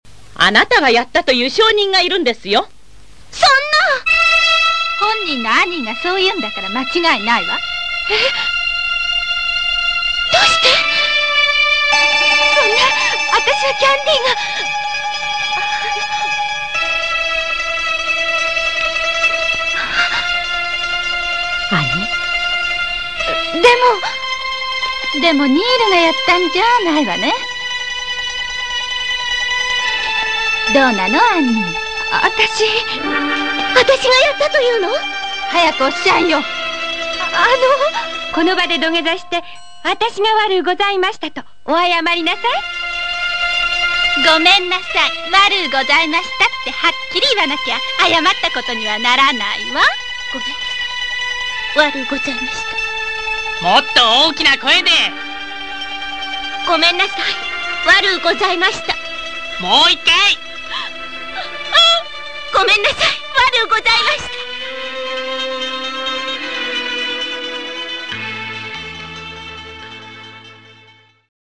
でもどっちかというとキャンディ・キャンディのアニーの声。
弥生のセリフをキャンディ・キャンディのセリフにまぜてみたから聞いて